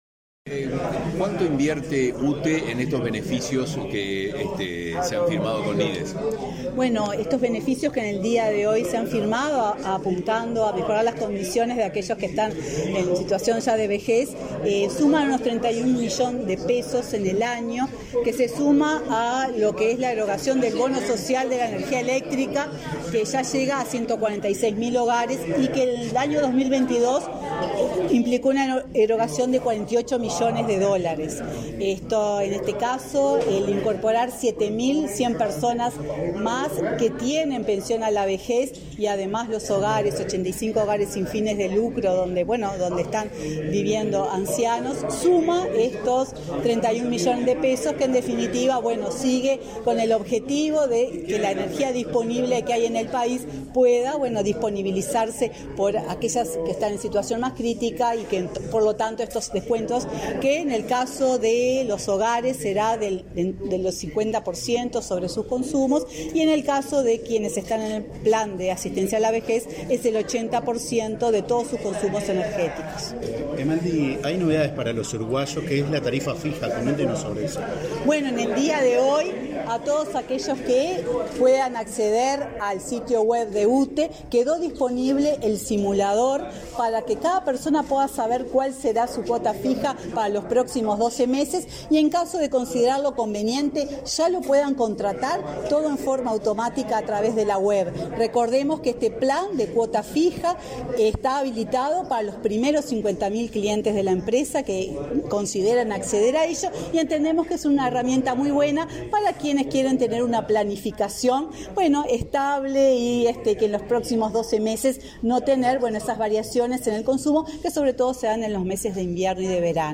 Declaraciones a la prensa de la presidenta de UTE, Silvia Emaldi
Tras participar en el acto de presentación de los nuevos beneficios en las tarifas de consumo de energía eléctrica para hogares de ancianos y usuarios